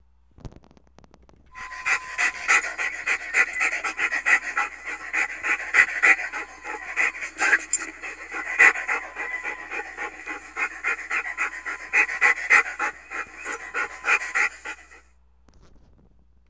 Dog Panting.wav